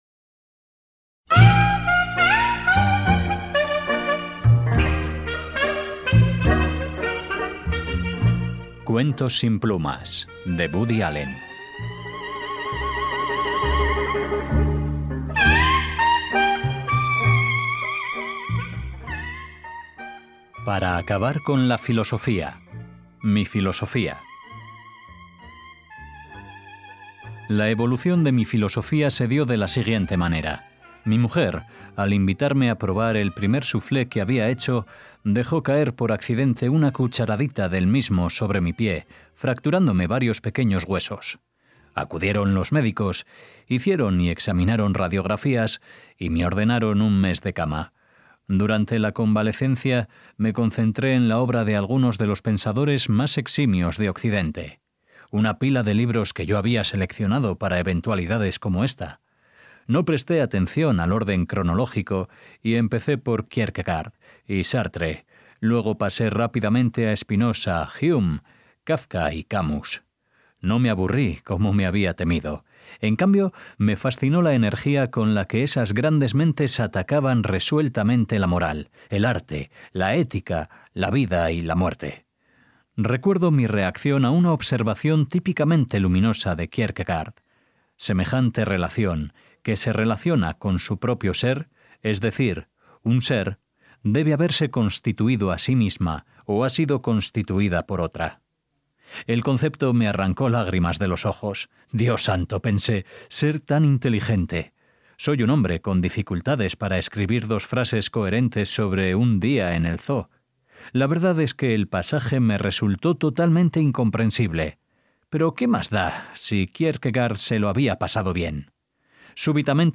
“CUENTOS SIN PLUMAS”, DE WOODY ALLEN - Iniciamos una nueva sección dedicada a la lectura de algunos cuentos de la pluma (aunque en el título nos promete que sin ellas) del genial cineasta estadounidense.